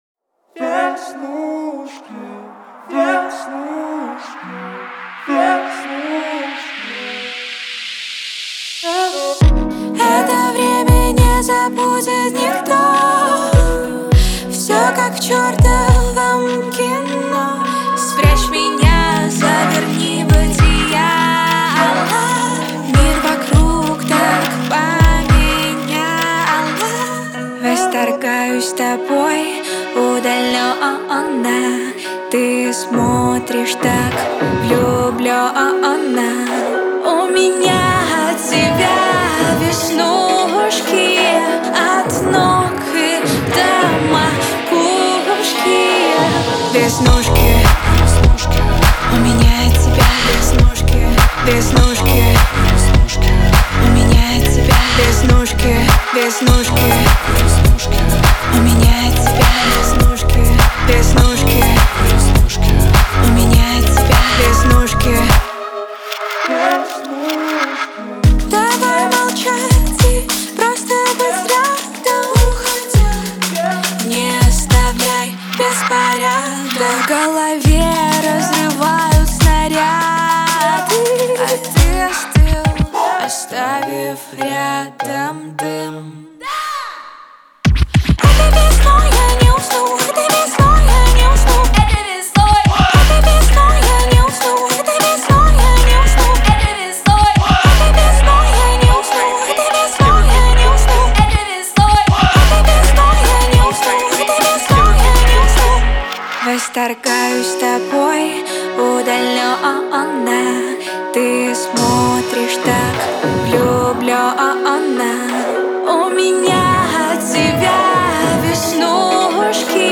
это яркая и зажигательная песня в жанре поп-рок